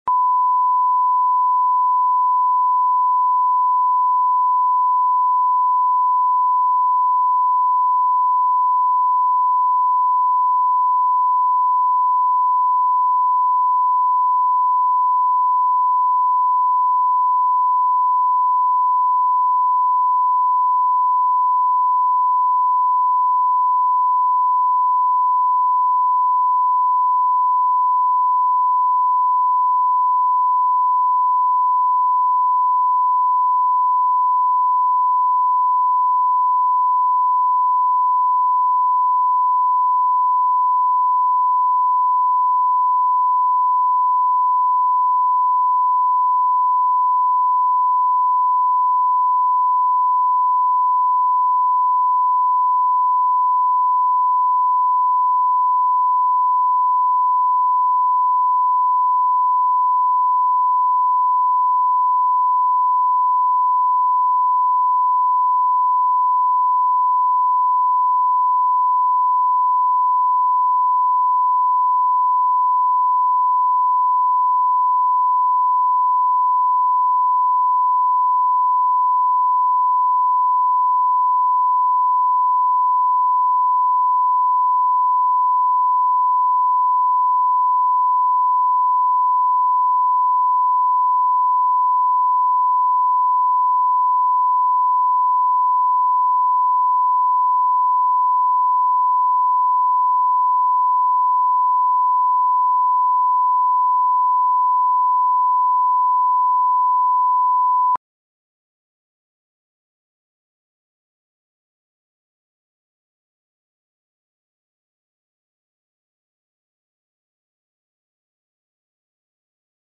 Аудиокнига Сыновья